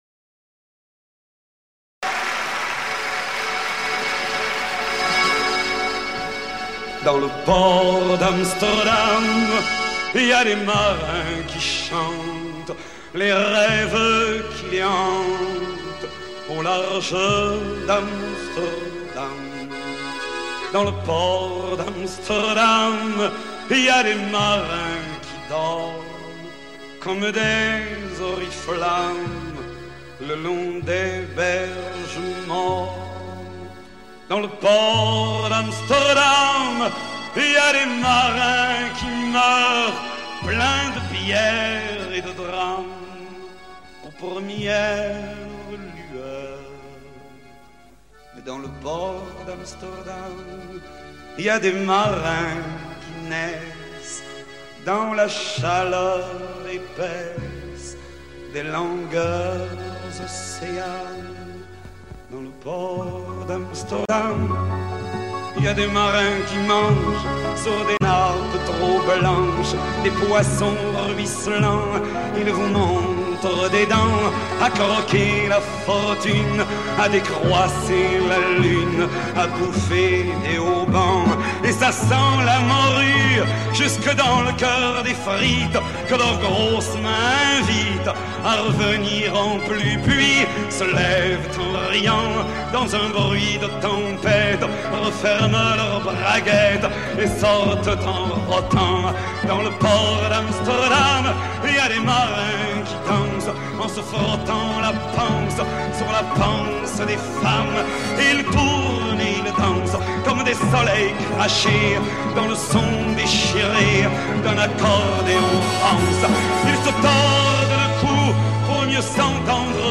Két francia sanszonénekest különösen szeretett.